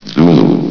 vox sounds
zulu.ogg